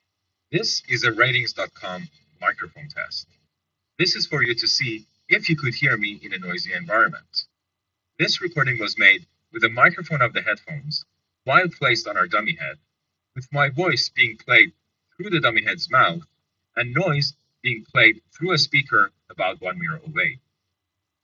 Or, if you want to hear how they handle background noise, you can listen to our recordings taken in
quiet and